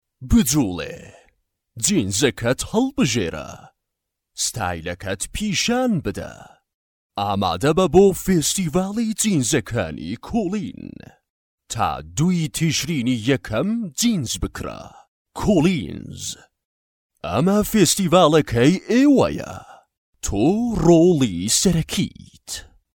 Male
Adult
Commercial